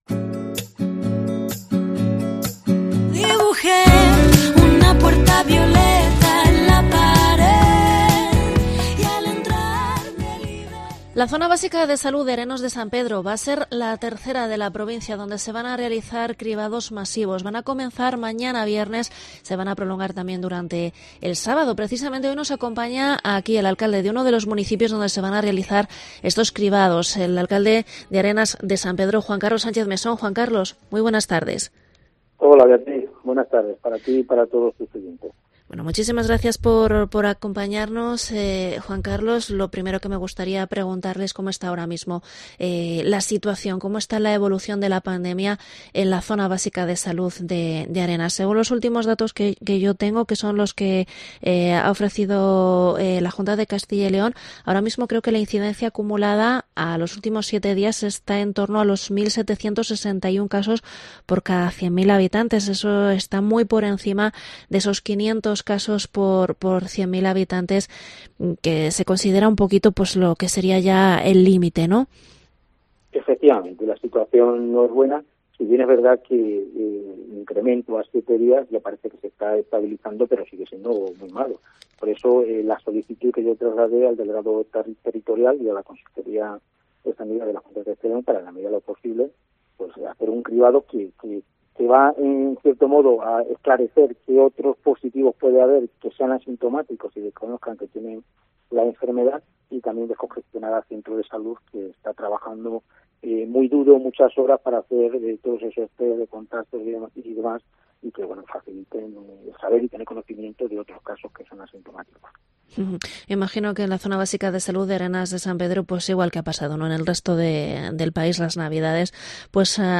Entrevista al alcalde de Arenas de San Pedro, Juan Carlos Sánchez Mesón sobre los cribados en la zona